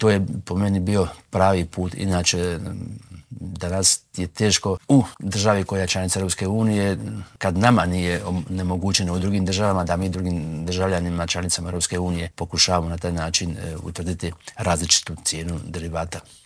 ZAGREB - U nedjelju je obilježena šesta godišnjica zagrebačkog potresa, što se još treba napraviti po pitanju poslijepotresne obnove u Intervjuu tjedna Media servisa pitali smo potpredsjednika Vlade i ministra prostornog uređenja, graditeljstva i državne imovine Branka Bačića.